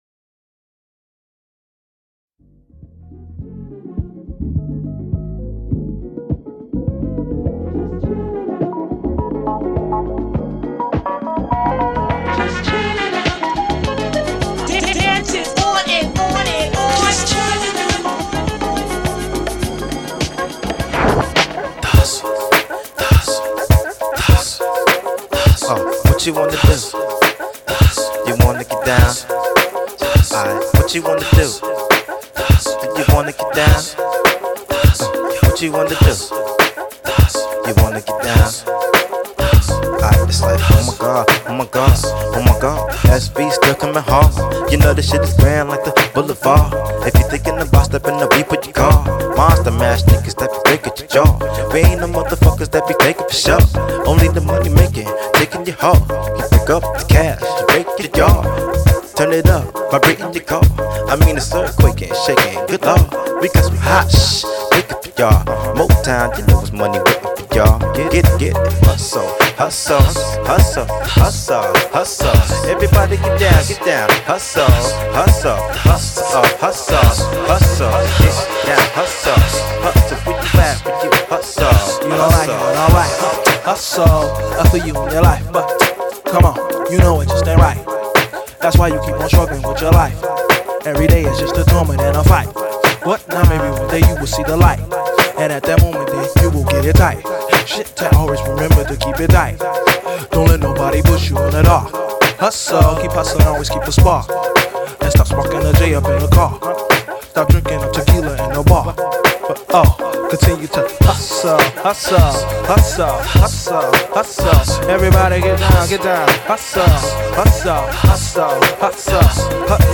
踊りたくなるような疾走感溢れるビート感